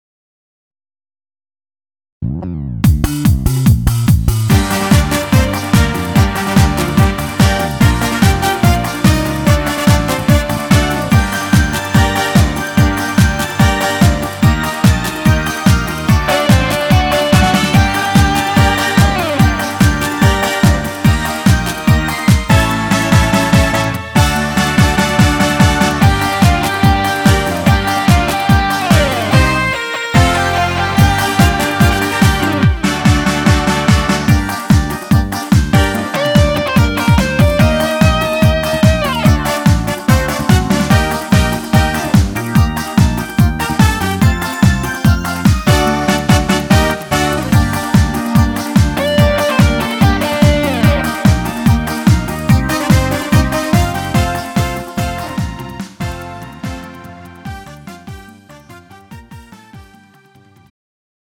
음정 원키 3:30
장르 가요 구분 Pro MR